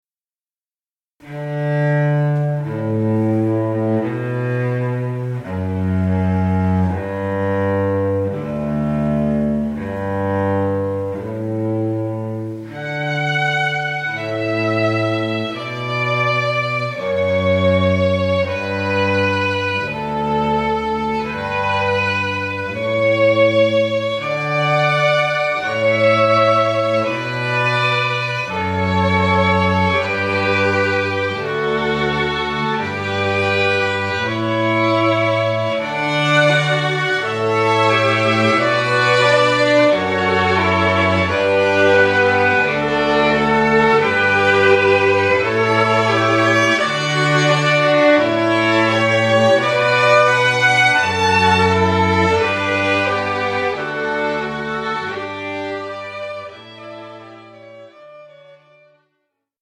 Delicato Strings | Talented and Experienced String Duo, Trio and Quartet
A talented string quartet with a sound that exhibits quality and experience.